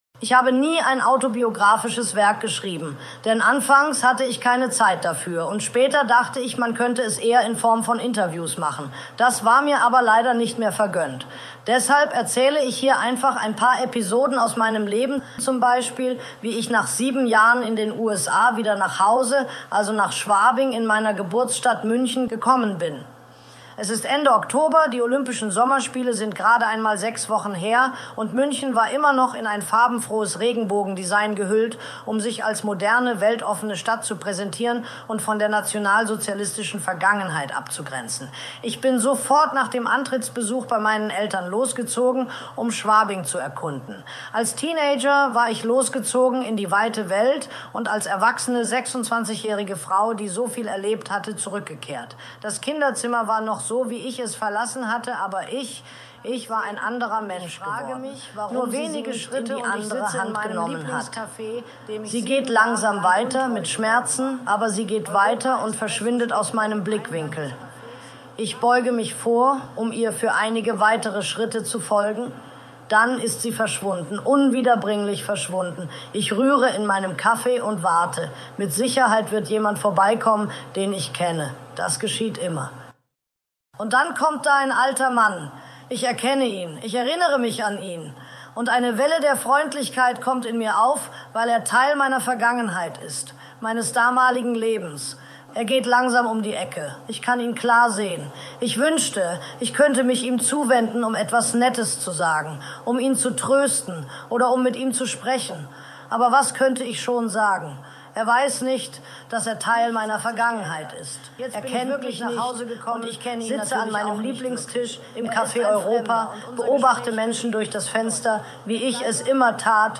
Verfügbar ab dem Frühsommer 2026! – Vorab findet man an dieser Stelle aber bereits vier unterschiedliche Sprachbeispiele:
(Teaser mit der KI-B Stimme der 1980er Jahre)